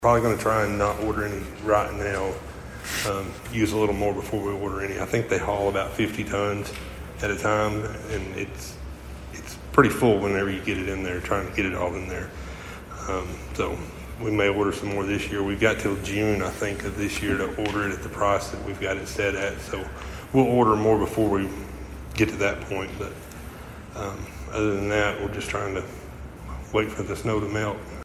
During Monday night’s meeting, the Princeton City Council moved forward with plans for sidewalk repairs, received updates on the winter storm’s impact, and learned that a longtime city employee is stepping down.